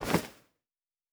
pgs/Assets/Audio/Fantasy Interface Sounds/Bag 03.wav at master
Bag 03.wav